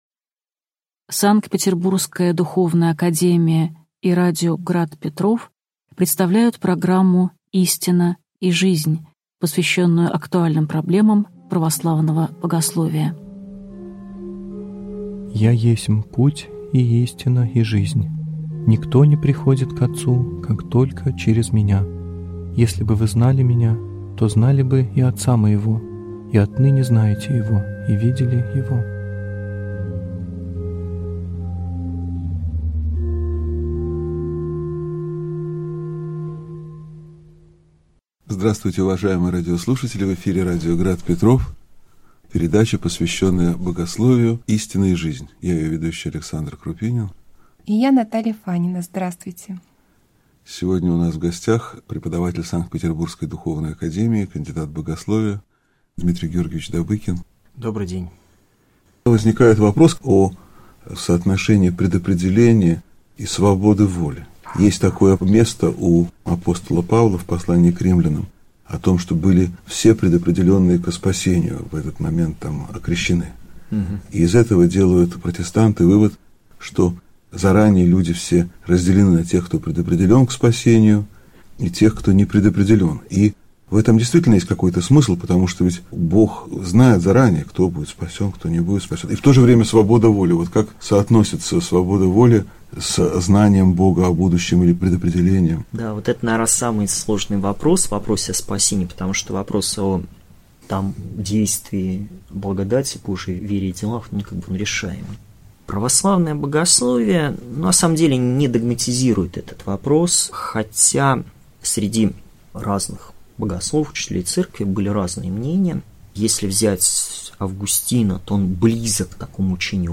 Аудиокнига Учение о Спасении (часть 2) | Библиотека аудиокниг